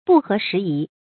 注音：ㄅㄨˋ ㄏㄜˊ ㄕㄧˊ ㄧˊ